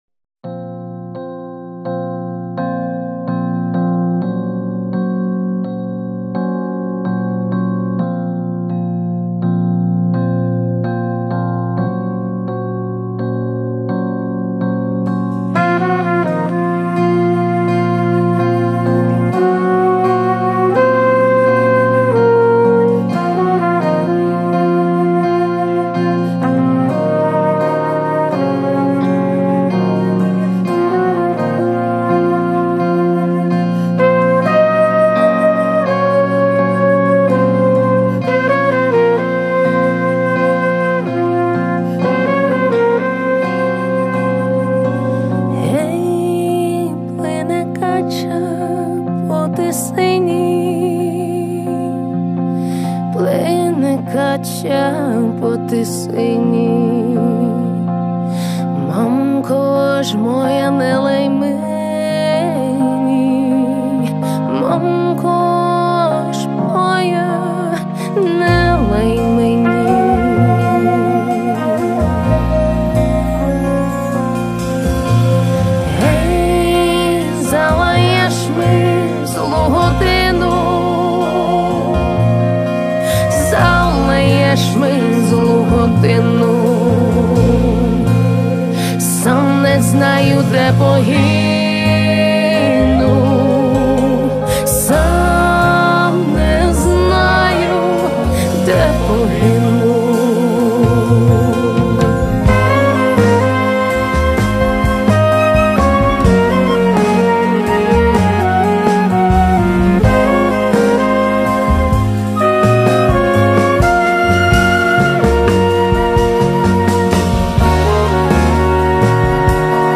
• Жанр:Народна